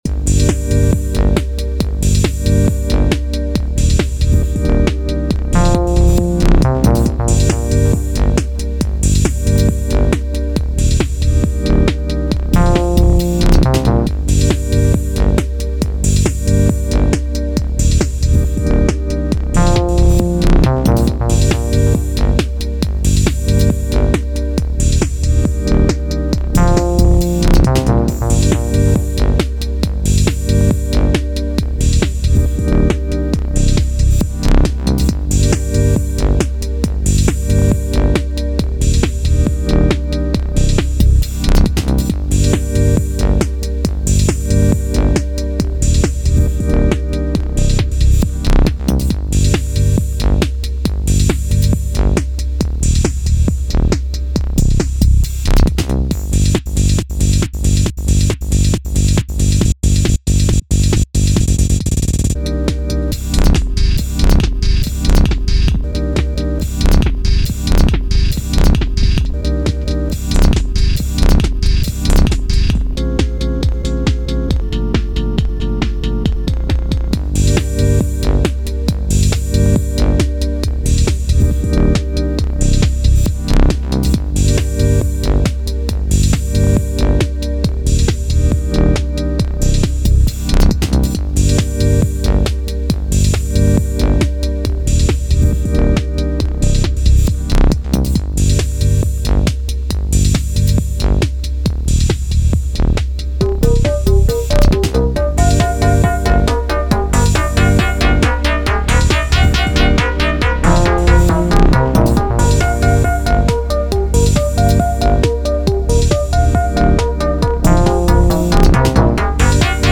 okay mastodon, here's some jams for the weekend.